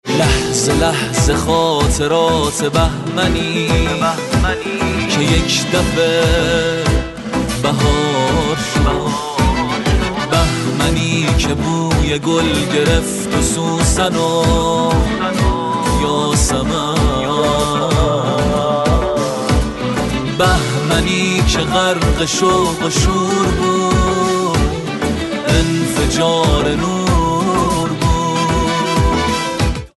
رینگتون پرانرژی و باکلام